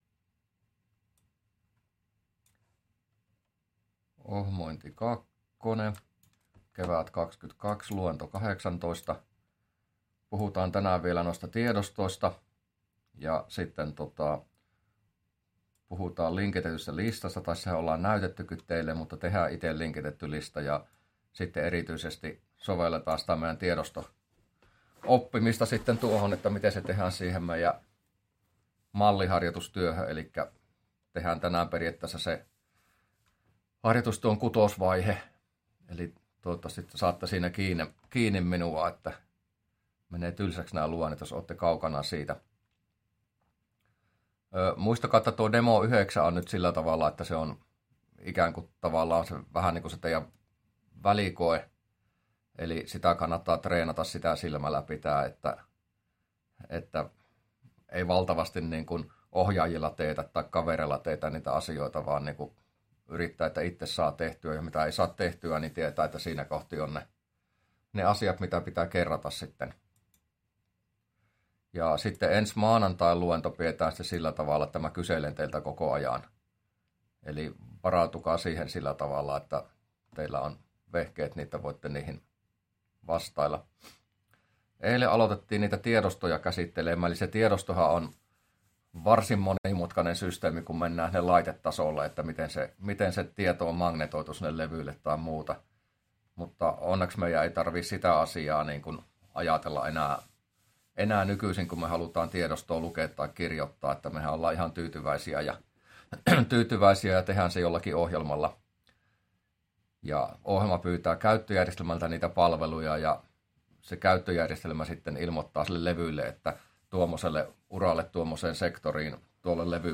luento18a